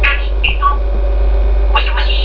本当はみんなもっと高めの明瞭な美声です。テープレコーダー経由の電話音質なので、
よいしょ〜！ 8K 私が座ろうとすると言います。